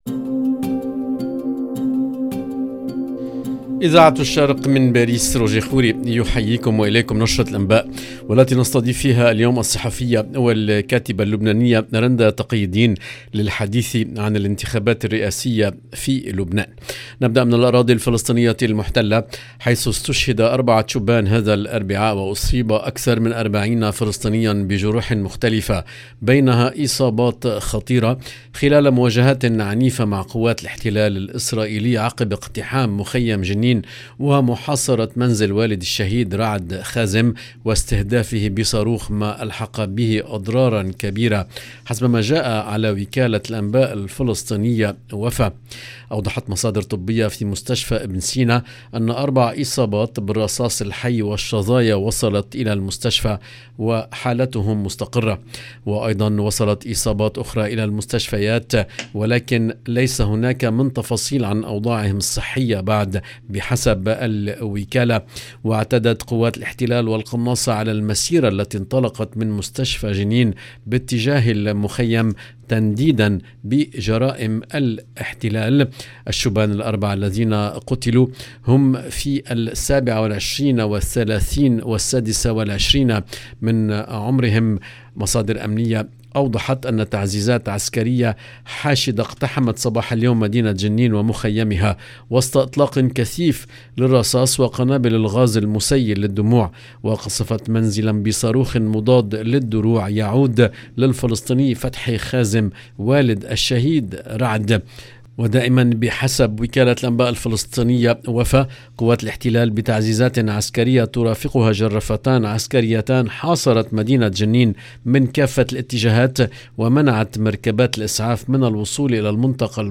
LE JOURNAL DU SOIR EN LANGUE ARABE DU 28/9/2022